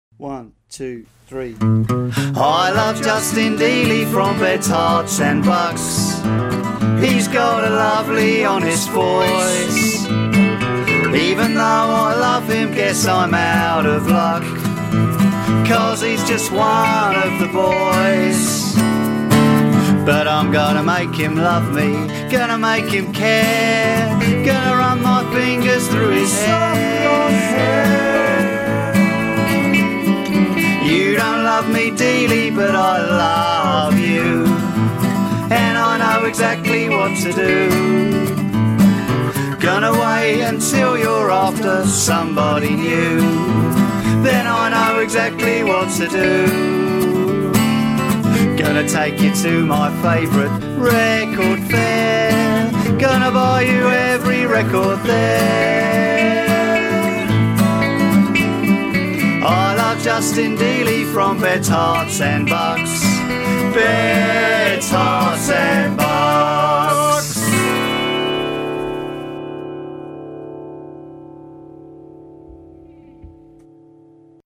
A song